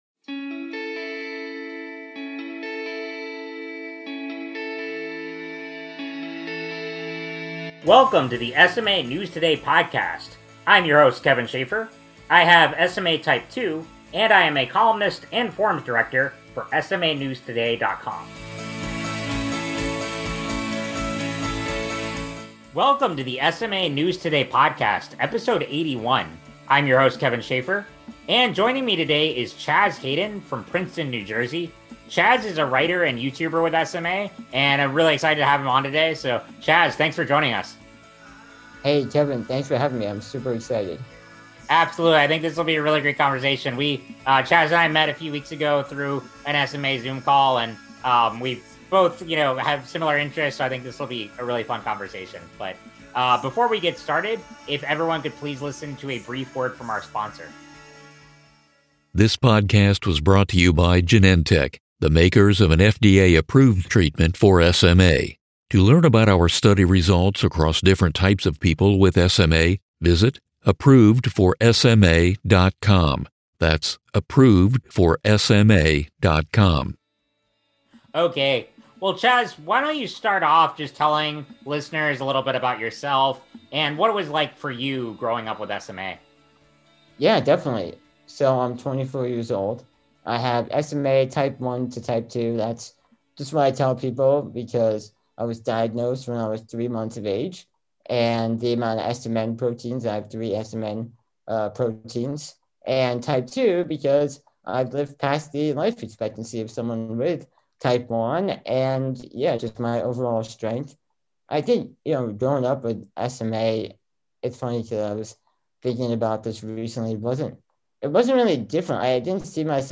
#81: Interview